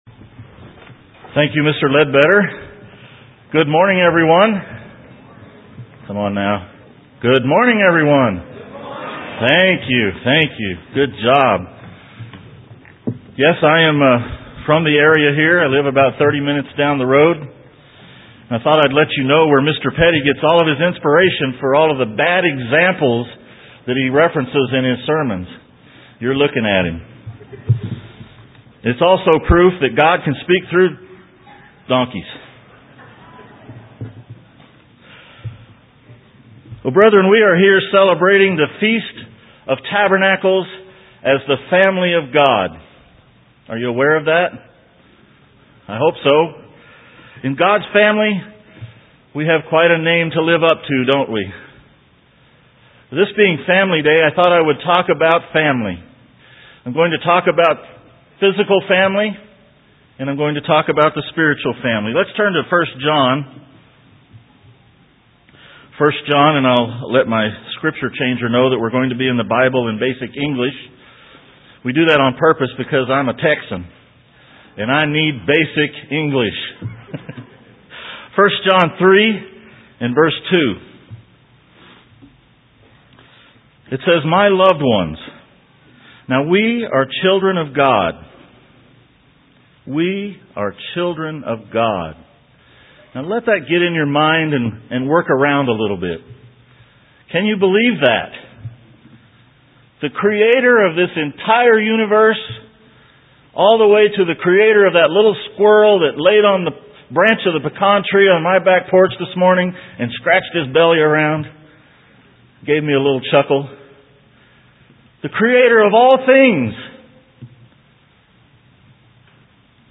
Second day FOT New Braunfels.
UCG Sermon Studying the bible?